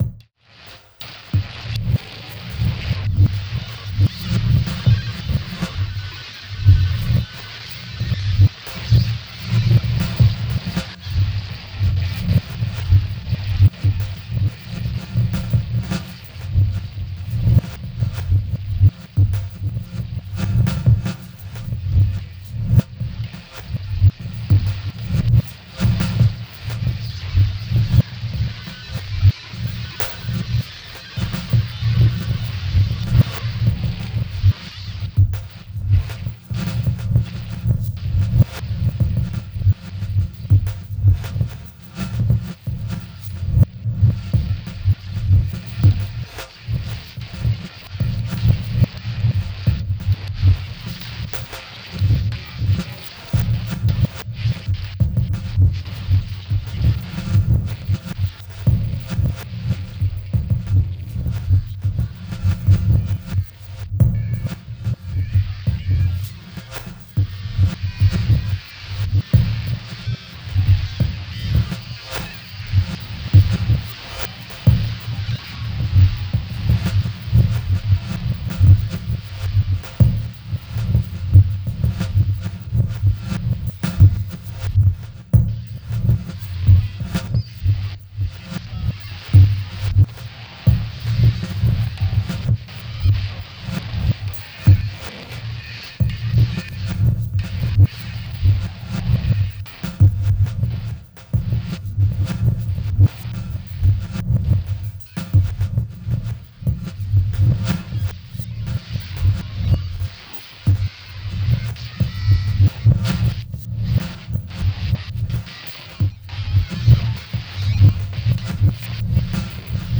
各曲ともに聴き進む内に、独特異様な酩酊感に包まれていくような...。
どうかこの不可思議な律動に身を委ね、あなたの魂を奔放に舞踏させながら、お楽しみください。